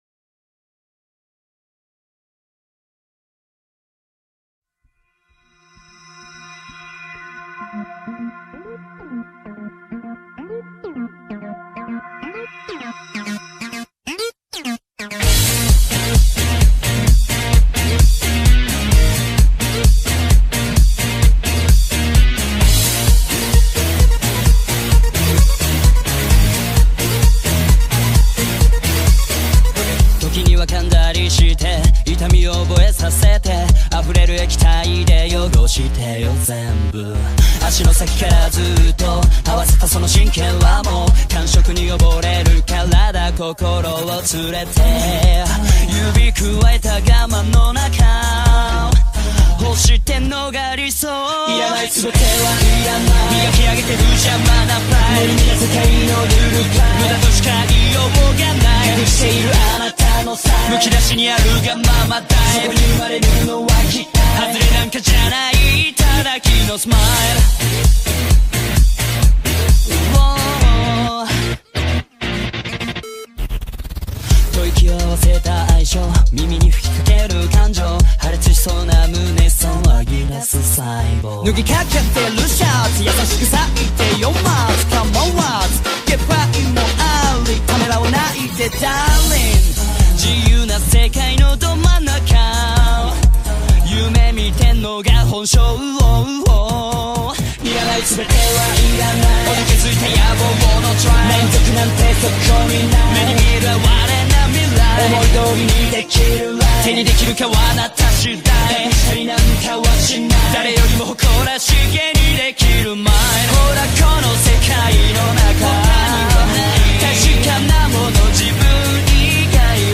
ytmp3.pageMikaela_Shindo_sings_Ifudodo_Pomp_Circumstance_.mp3